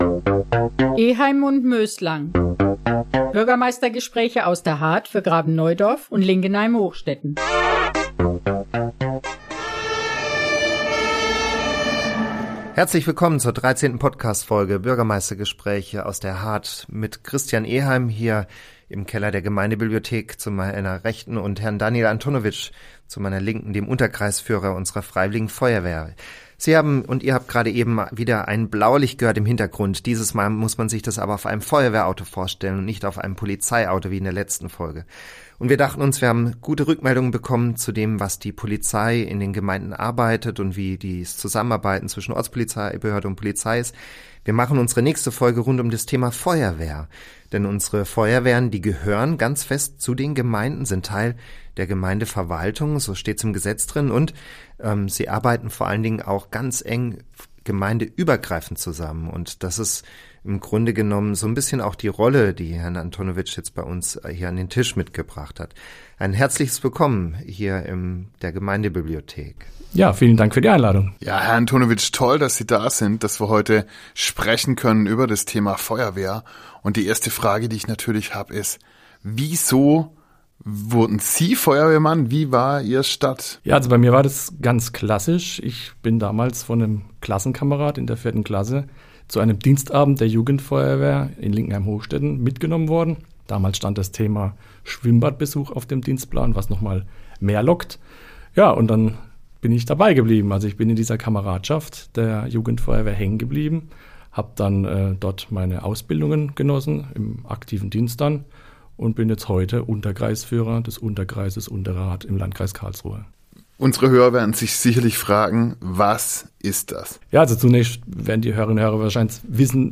Bürgermeistergespräche aus der Hardt für Graben-Neudorf und Linkenheim-Hochstetten